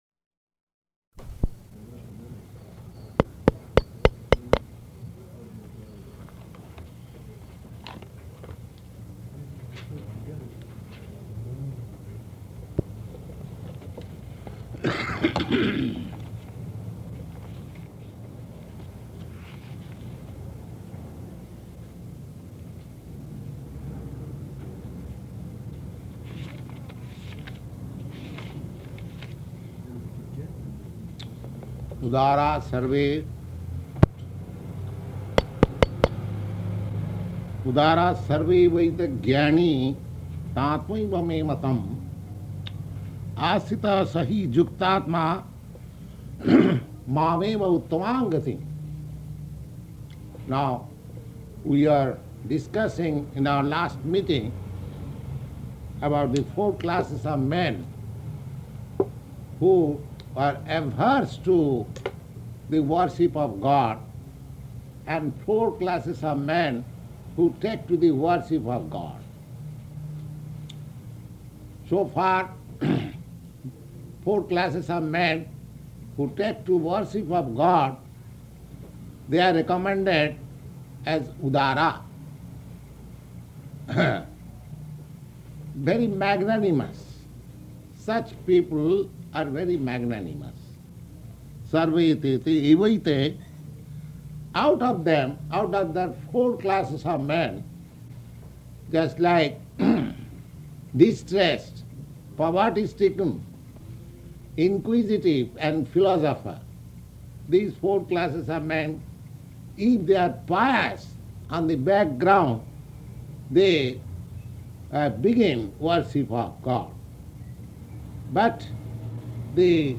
Location: New York
[taps microphone]